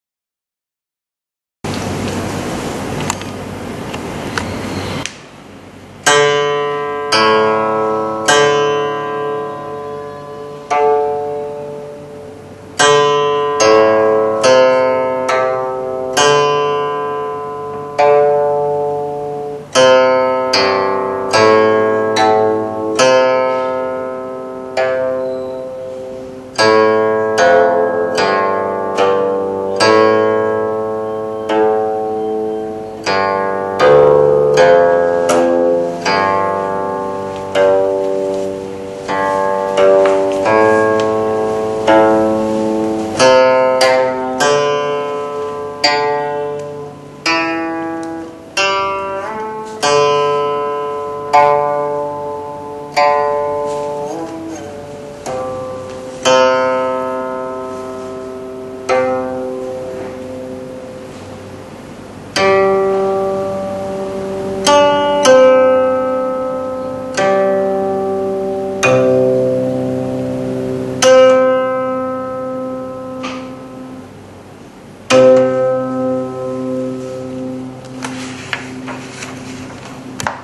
古琴教学示范《仙翁操》